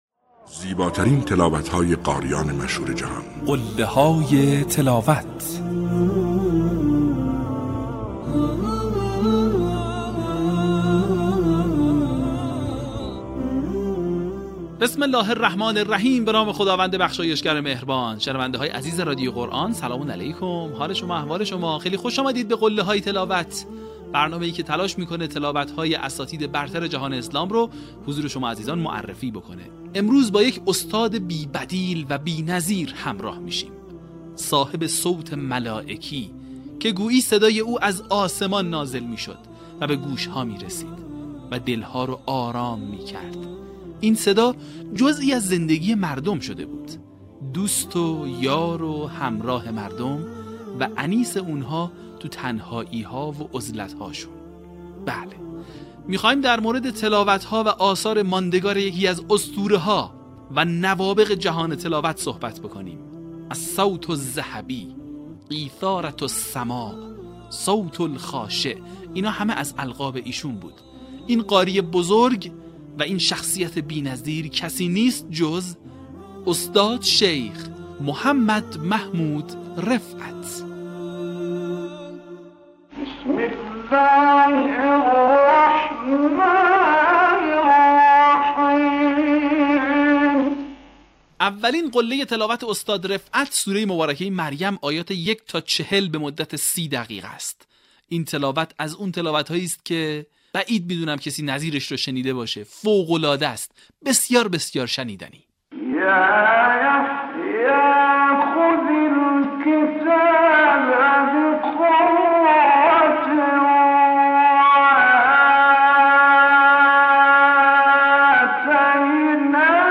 در قسمت سی‌وششم فراز‌های شنیدنی از تلاوت‌های به‌یاد ماندنی استاد محمد رفعت را می‌شنوید.
برچسب ها: قله های تلاوت ، فراز تقلیدی ، تلاوت ماندگار ، محمد رفعت